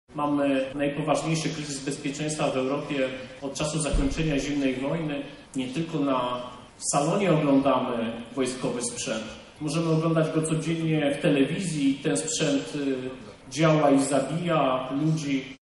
Ta edycja Salonu przypada w szczególnym czasie – Mówił podczas uroczystego otwarcia MSPO Minister Obrony Narodowej Rzeczpospolitej Polskiej, Tomasz Siemoniak: